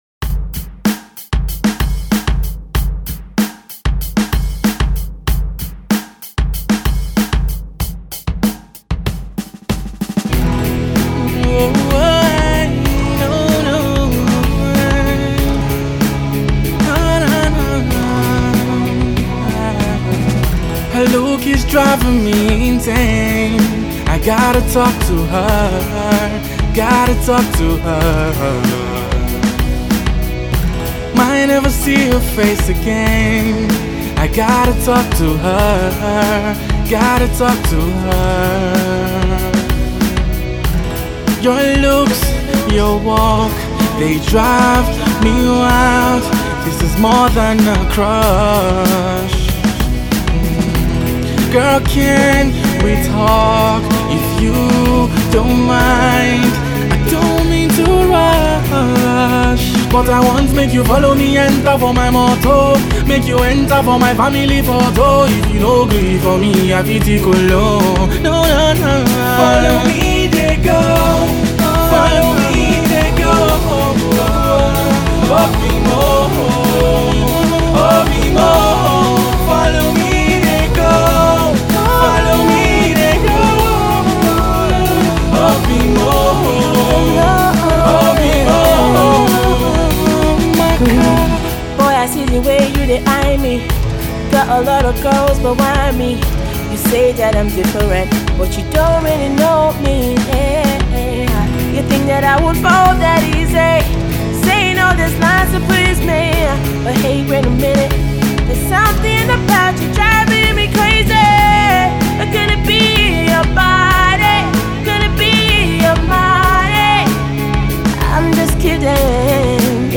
a real love song on which he’s assisted by up-and-comer